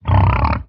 assets / minecraft / sounds / mob / hoglin / idle10.ogg